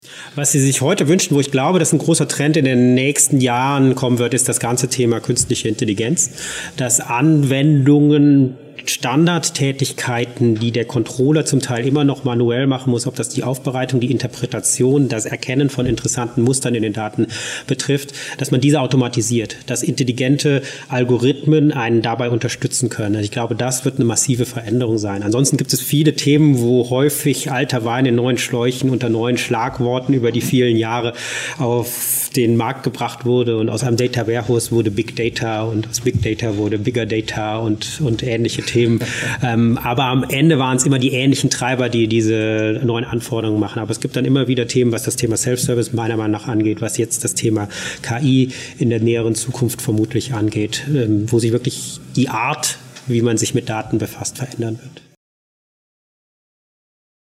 Das Hörbuch für erfolgreiche Controller & CFO